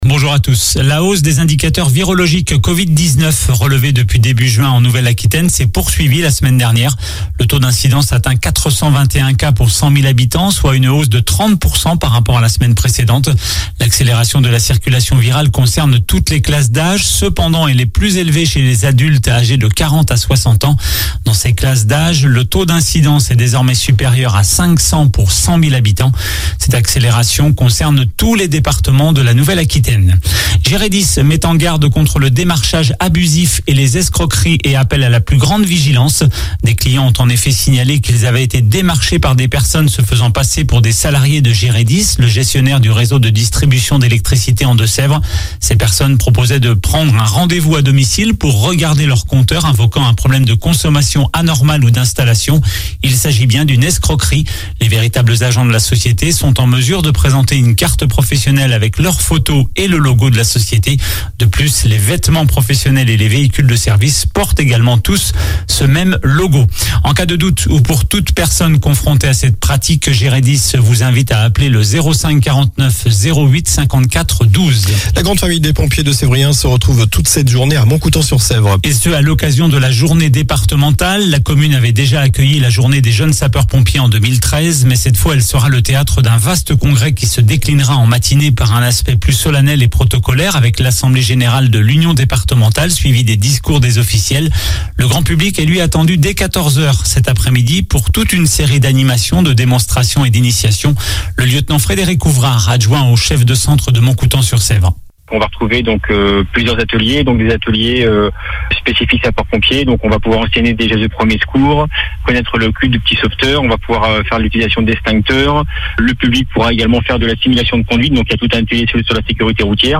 Journal du samedi 25 Juin